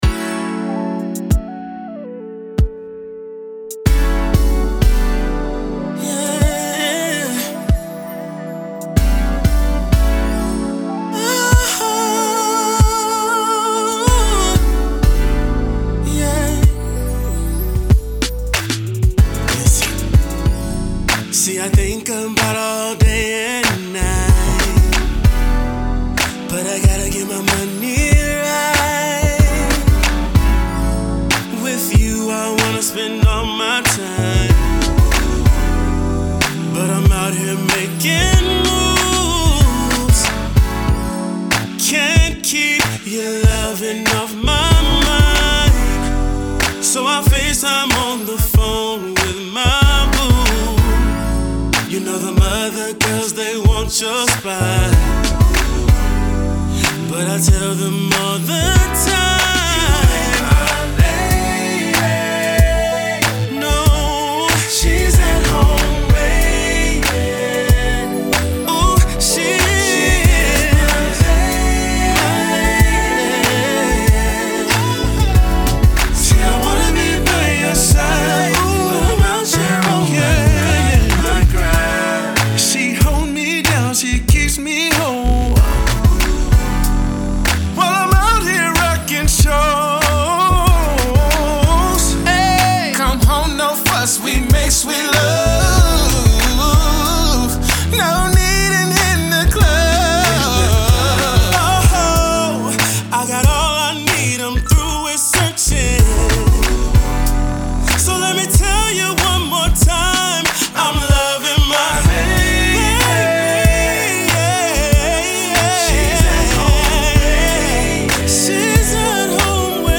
RnB
QUALITY R&B